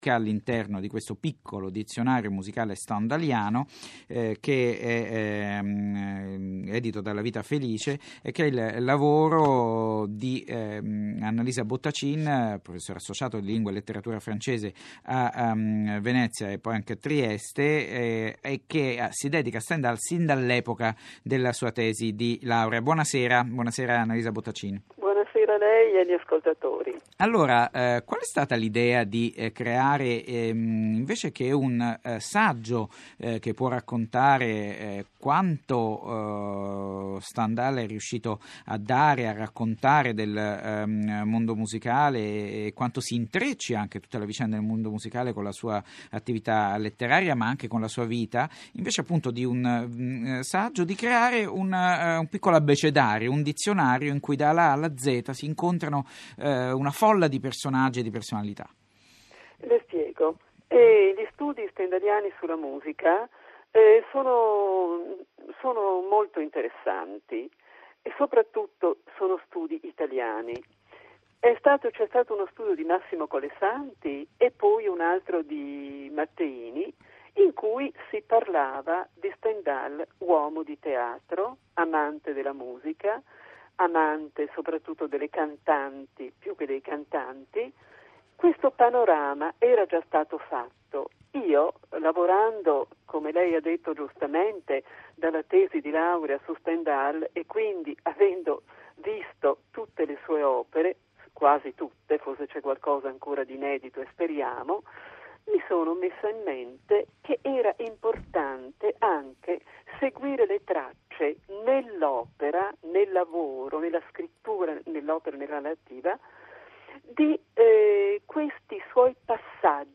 Riascolta l'intervista radiofonica, qui in allegato.